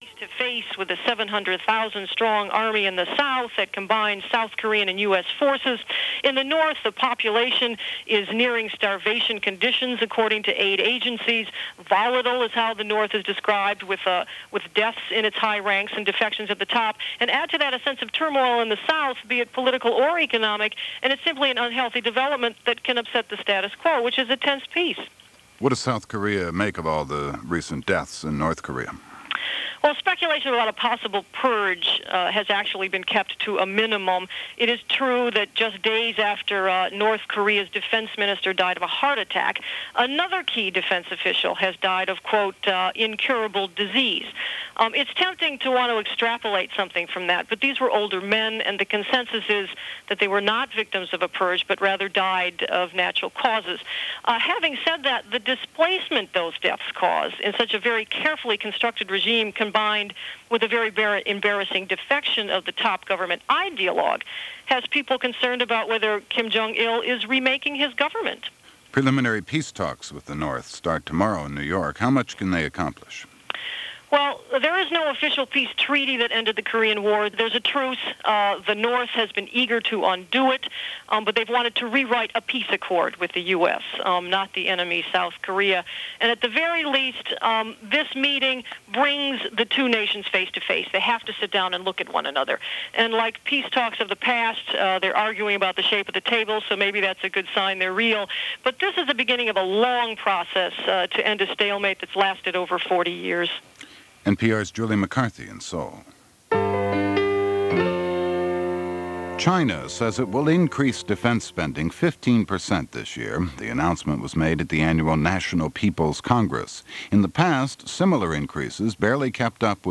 March 4, 1997 - Shakeups In North Korea - The Hostage Drama In Peru - A Word Or Two With Congressman Bernie Sanders.